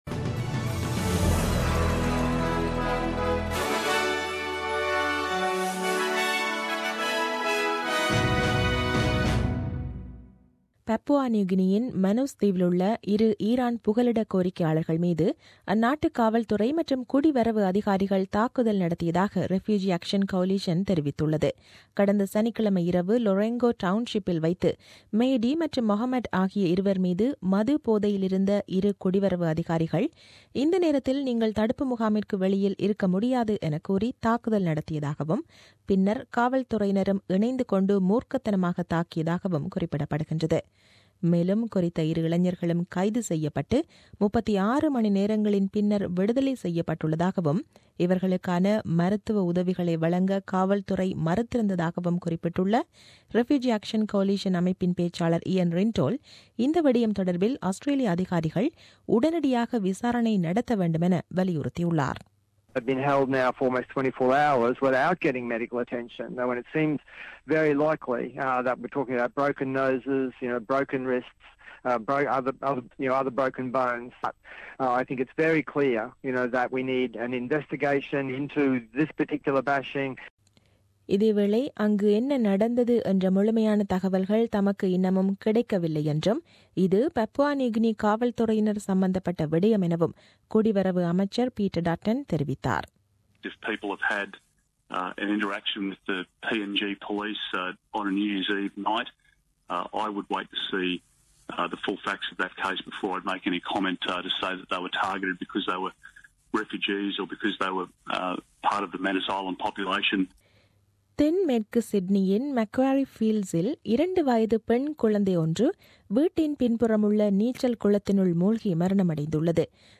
The news bulletin aired on 02 Jan 2017 at 8pm.